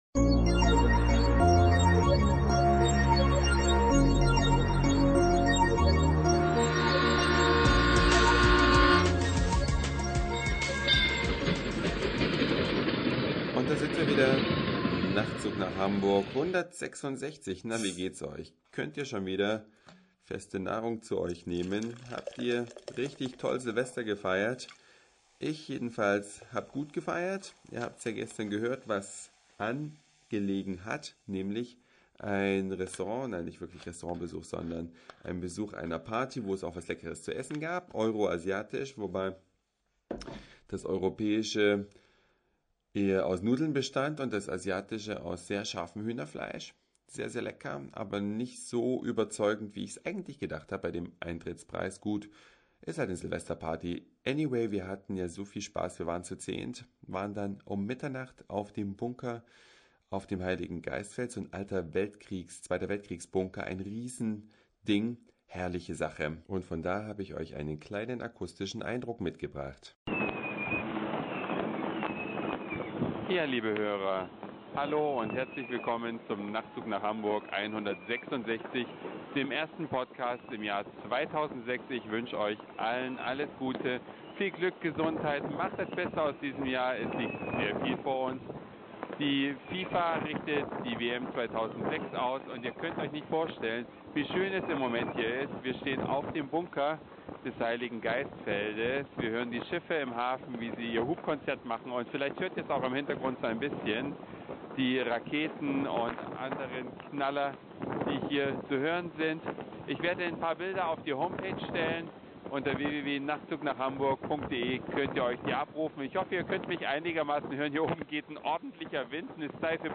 Eine Reise durch die Vielfalt aus Satire, Informationen, Soundseeing und Audioblog.
Silvester 2005/2006 mit Live-Bericht vom Dach des Bunkers auf dem